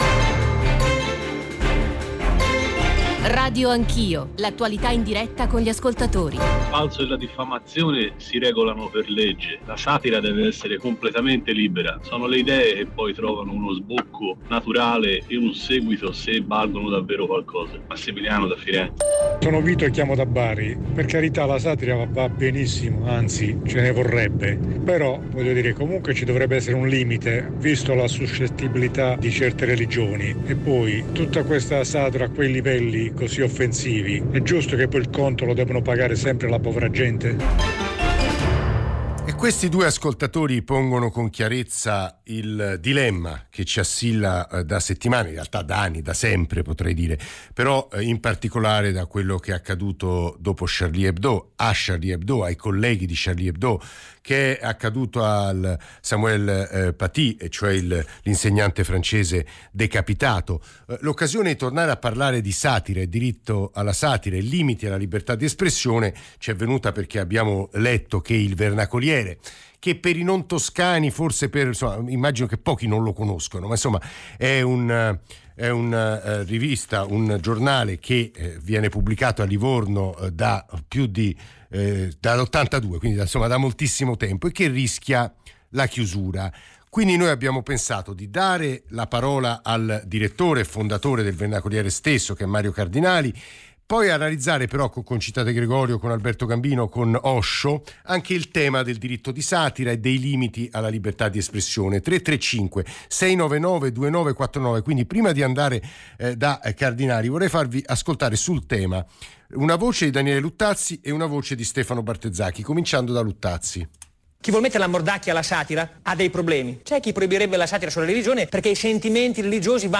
Intervista a Radio anch'io (Radio Rai 1)